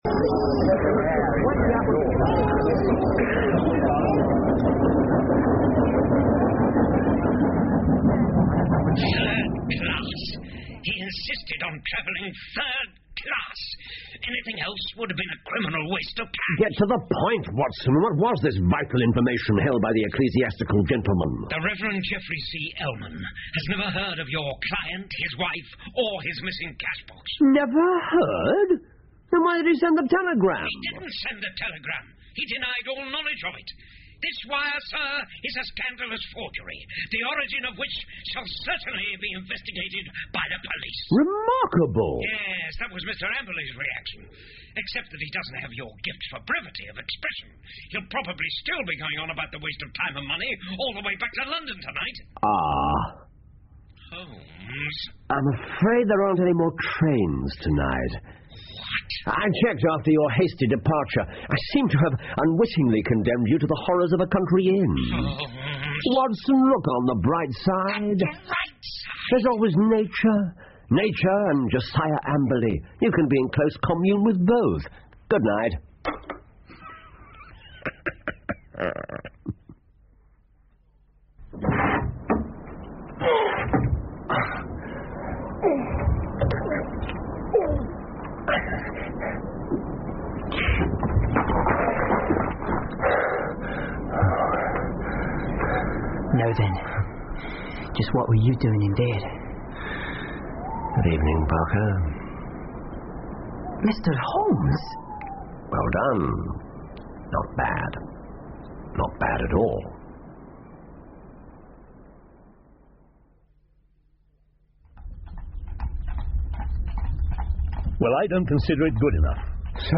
福尔摩斯广播剧 The Retired Colourman 7 听力文件下载—在线英语听力室